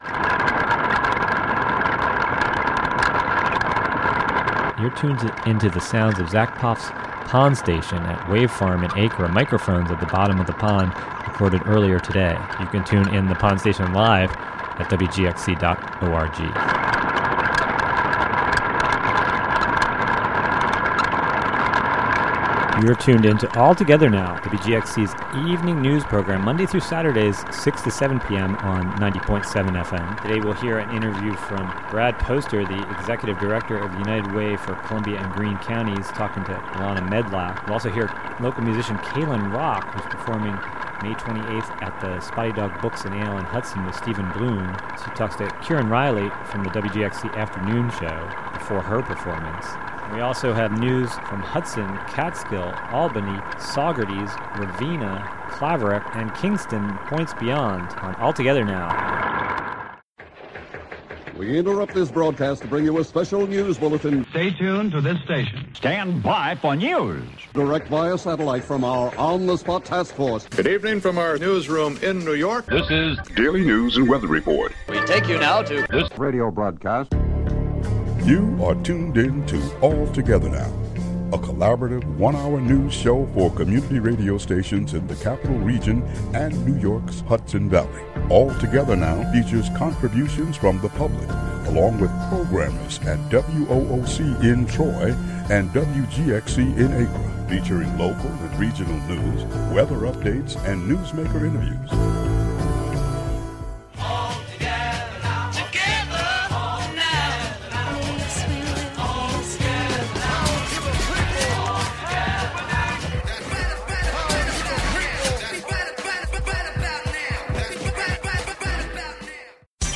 The show will also include other reports, segments, and stories. "All Together Now!" is a new evening news show brought to you by WGXC in Greene and Columbia counties, and WOOC in Troy.